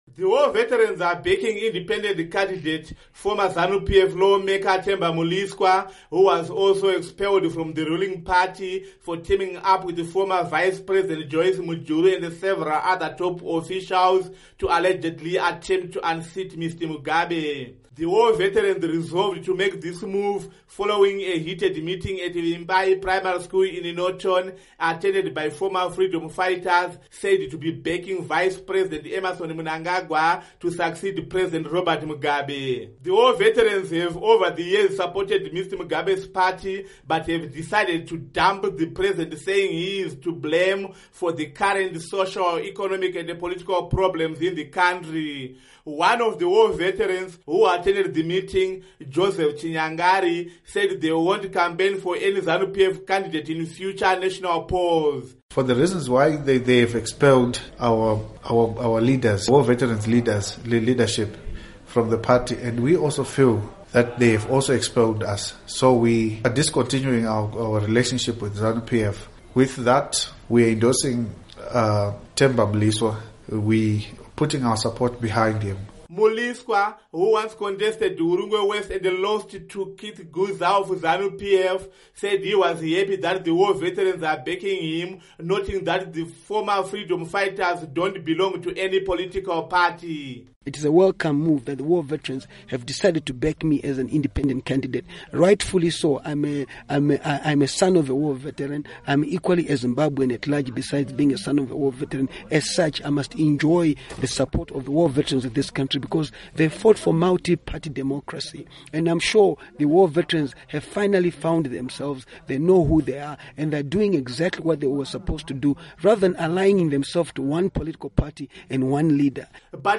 Report On Mliswa-Norton By-Election